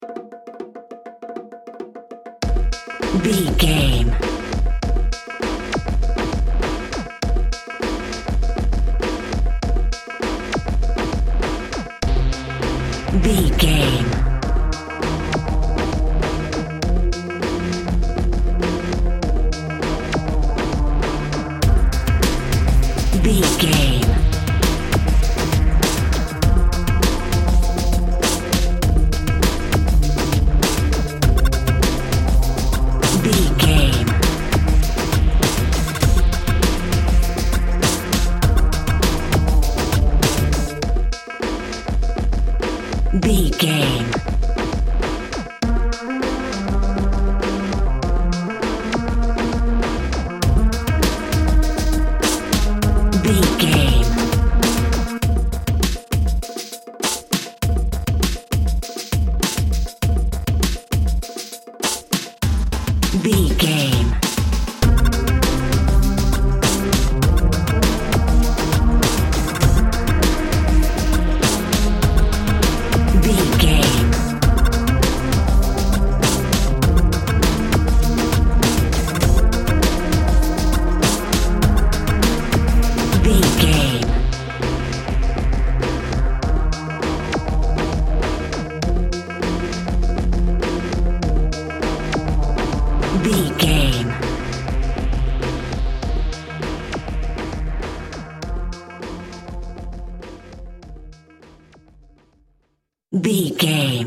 Aeolian/Minor
futuristic
hypnotic
industrial
mechanical
dreamy
smooth
powerful
drum machine
synthesiser
percussion
tabla
electronic
trance
drone
glitch
synth lead
synth bass